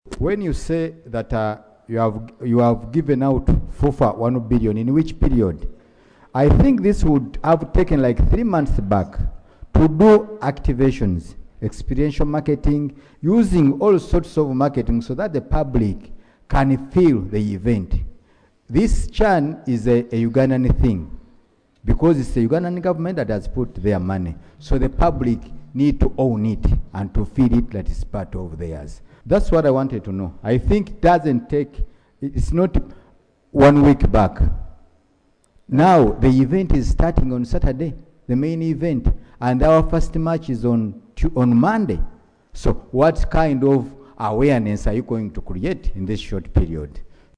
Legislators raised the concerns during debate on a statement by the Minister of State for Sports, Hon. Peter Ogwang on Wednesday, 30 July 2025.